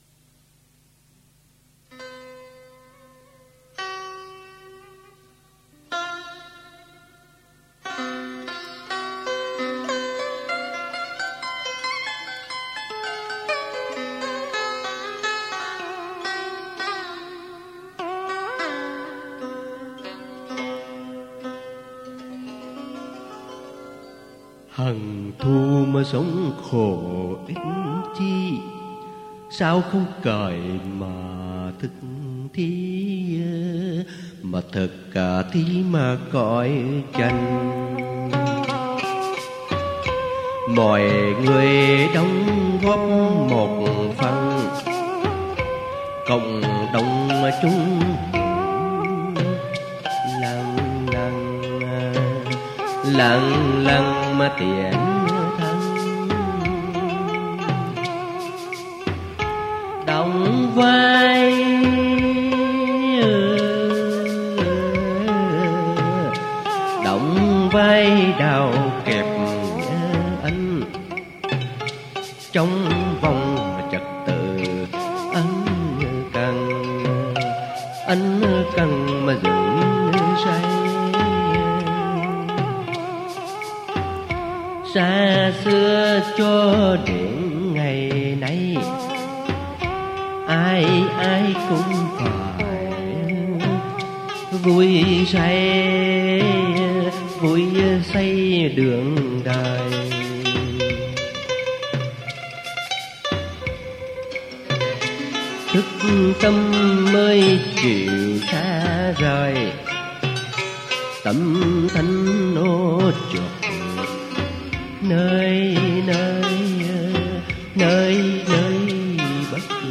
Dân Ca & Cải Lương
theo điệu bài chòi miền Trung